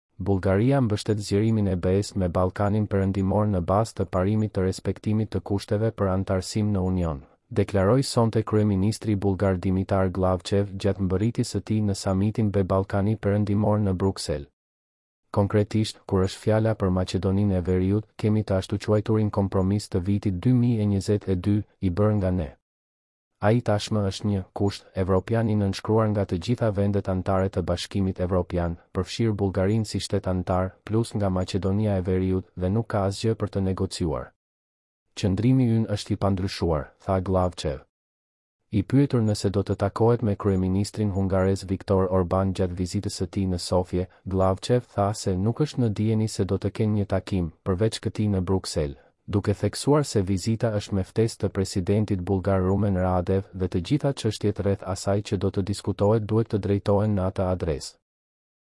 Bullgaria mbështet zgjerimin e BE-së me Ballkanin Perëndimor në bazë të parimit të respektimit të kushteve për anëtarësim në Union, deklaroi sonte kryeministri bullgar Dimitar Gllavçev gjatë mbërritjes së tij në Samitin BE-Ballkani Perëndimor në Bruksel.